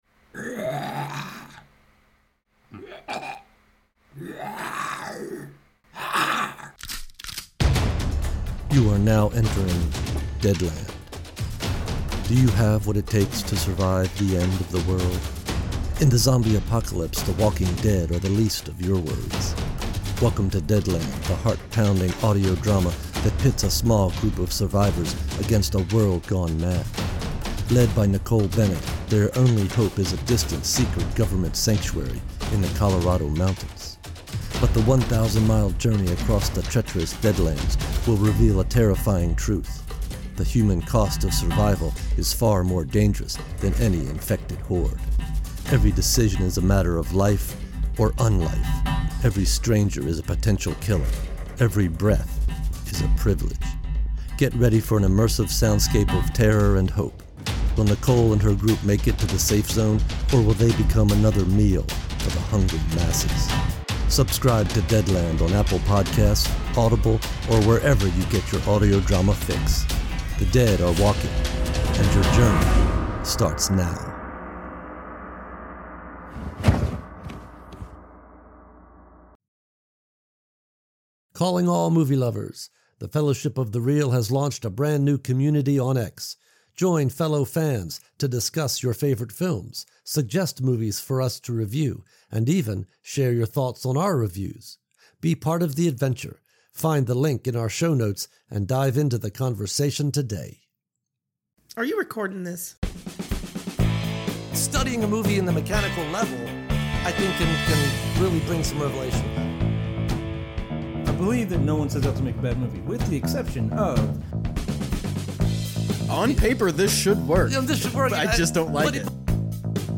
One movie review podcast to rule them all
Four movie fans meet to discuss, debate, and ultimately review movies of their own choosing.